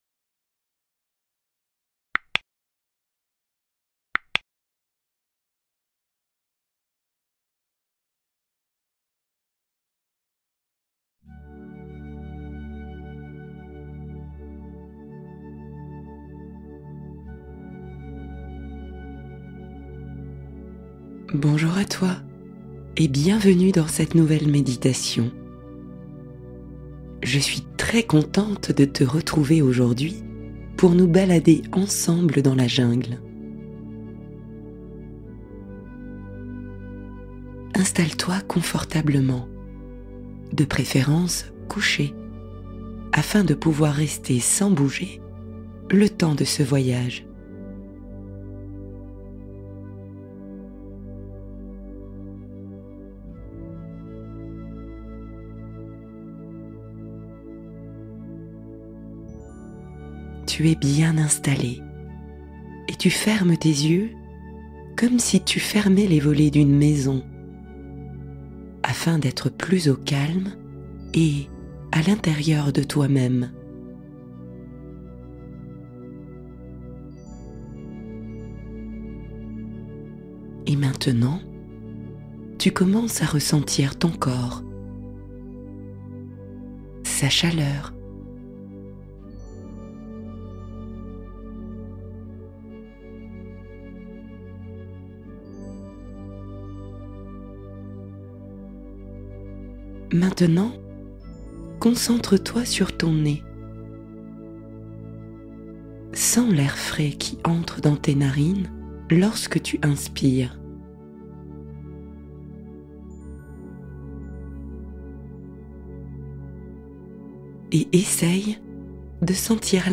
Accueillir l’énergie de l’arbre : méditation d’ancrage et de sérénité